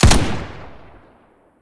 q3rally/baseq3r/sound/weapons/machinegun/machgf4b.ogg at b38c86580a45963aa71ed64ee6e1aad1ad8fdbeb